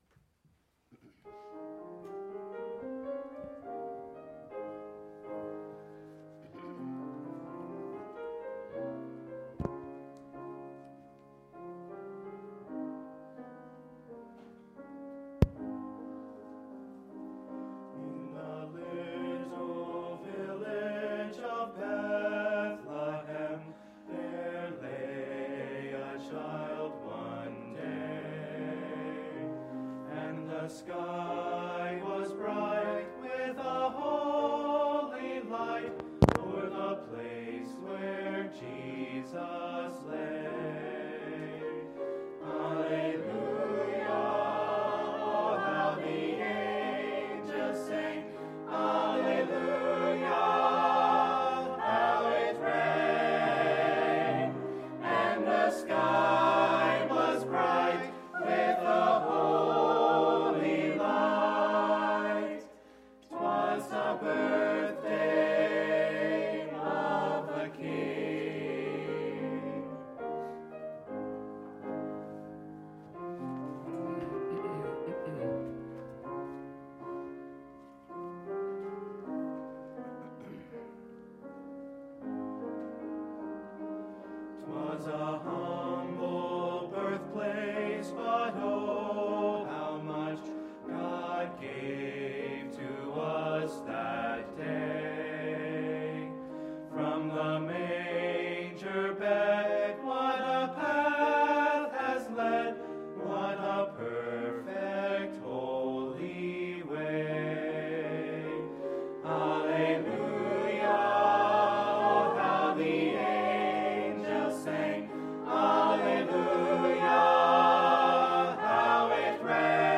2015 Adult’s Christmas Program
Music Programs